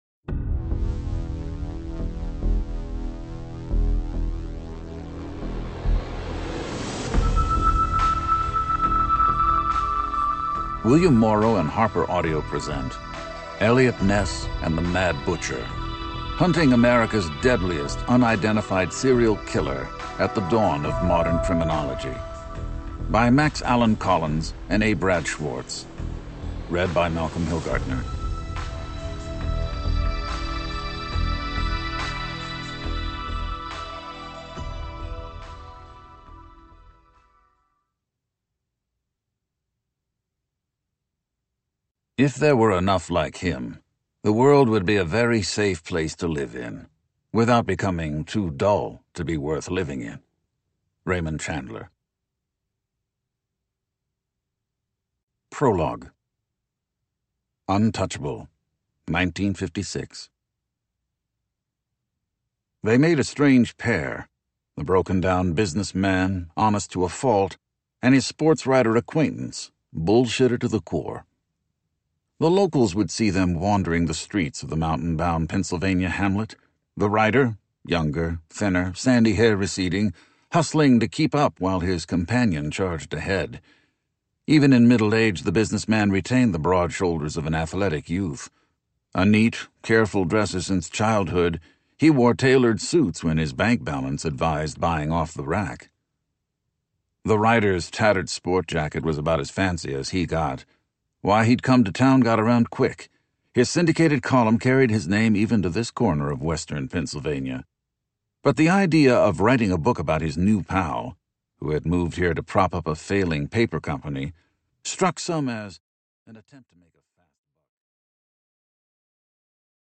Format: Eaudiobook, Audio Books, Nonmusical Sound Recording, Sound Recording, Electronic Resources Author: Collins, Max Allan. Title: Eliot Ness and the Mad Butcher [electronic resource] : hunting America's deadliest unidentified serial killer at the dawn of modern criminology / Max Allan Collins, A. Brad Schwartz.